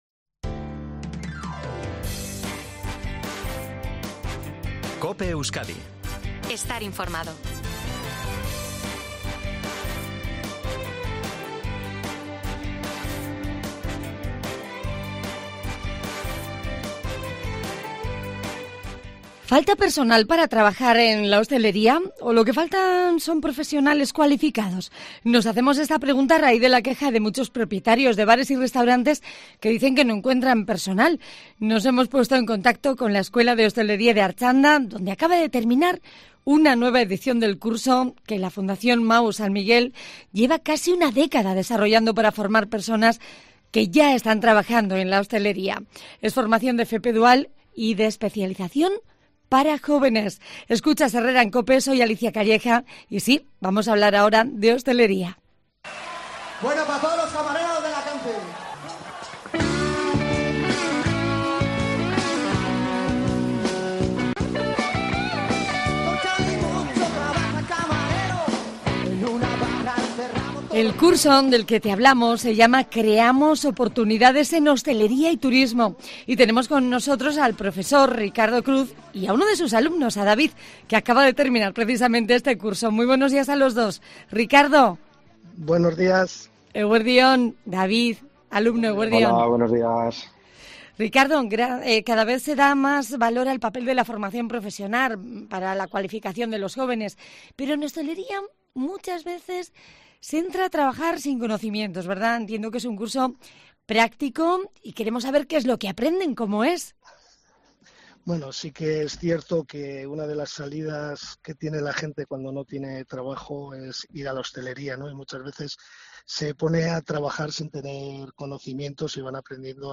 Docentes y alumnado de la Escuela de Hostelería de Artxanda detallan, en COPE Euskadi, las dificultades de miles de empresarios vascos para contratar a camareros formados